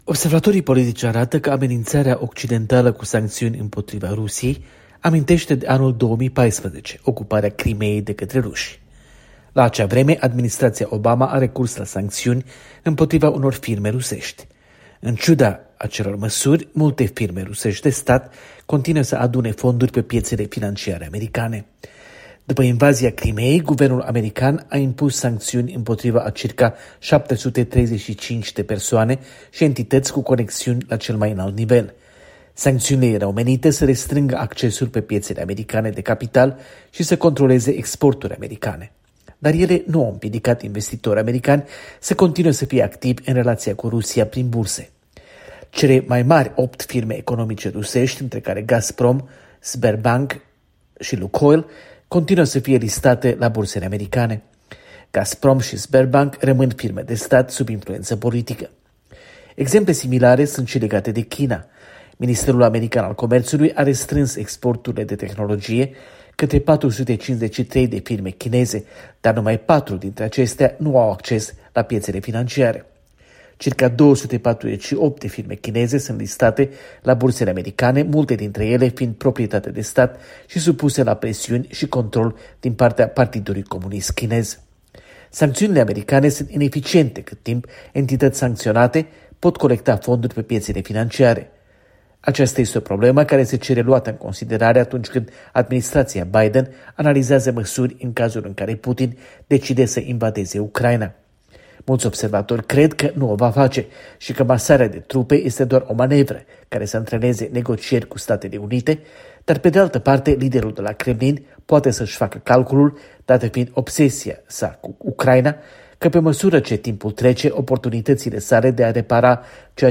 Corespondenta de la Washington